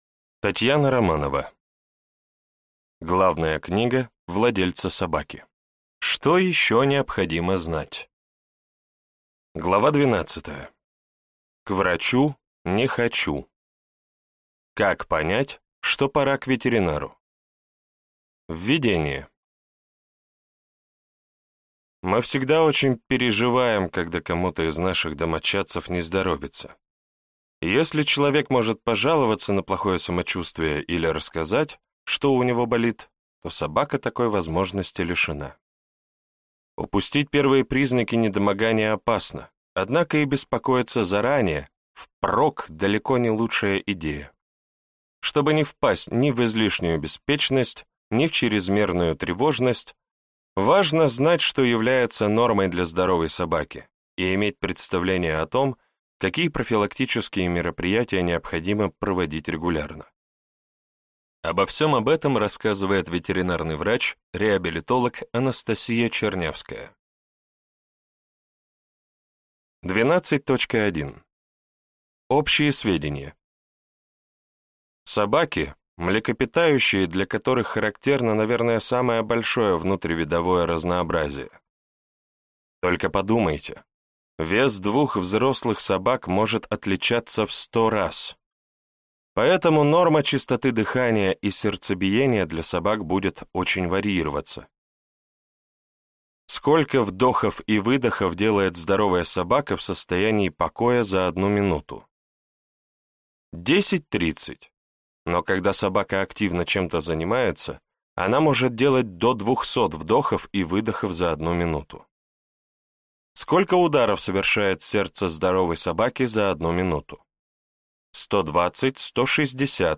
Аудиокнига Главная книга владельца собаки. Что ещё необходимо знать | Библиотека аудиокниг